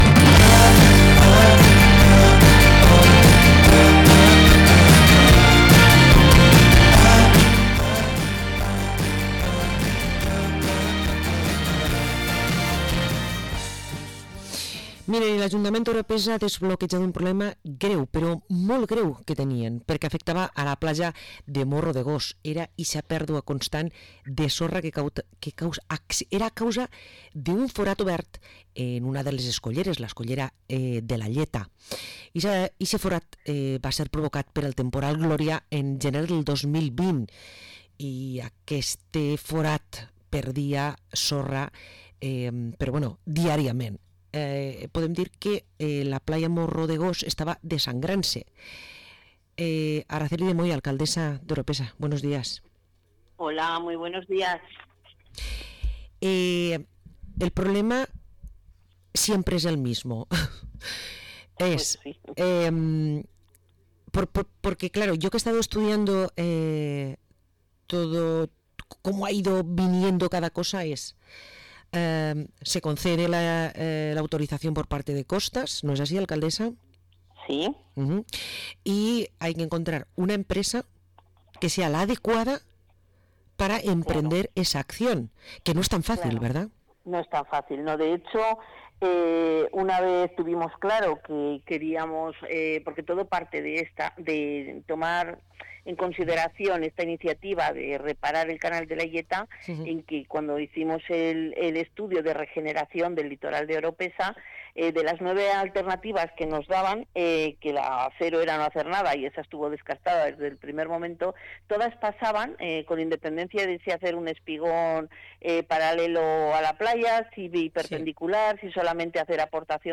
Parlem amb l´alcaldessa d´Orpesa, Araceli de Moya
15-04-25-Araceli-de-Moya-alcaldessa-Oropesa.mp3